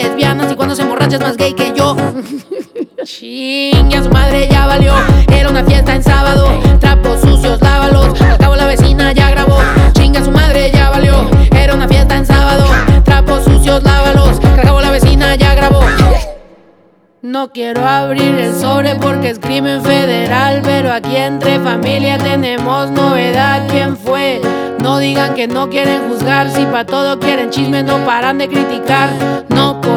Música Mexicana Latin Pop
Жанр: Поп музыка